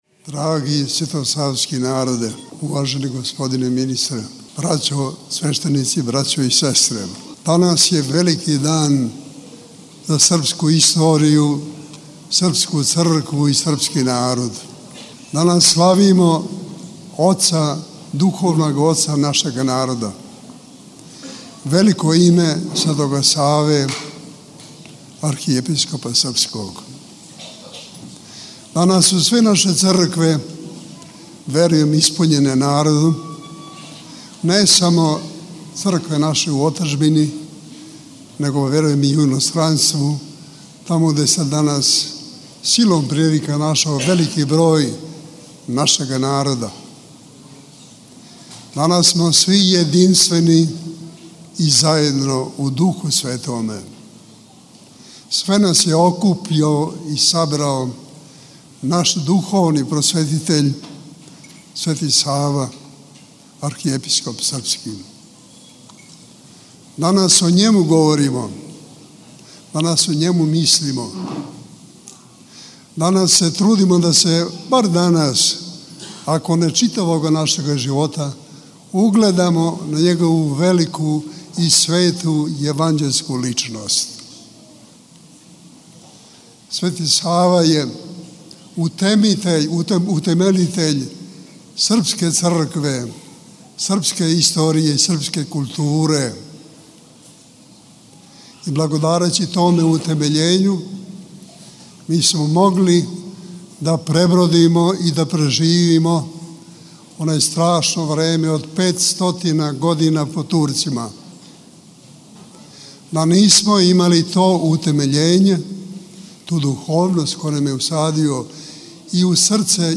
Његова Светост Патријарх српски г. Иринеј служио је 27. јануара 2020. године, на Савиндан, свету архијерејску Литургију у Спомен-храму Светог Саве на Врачару са Епископом ремезијанским Стефаном. Звучни запис беседе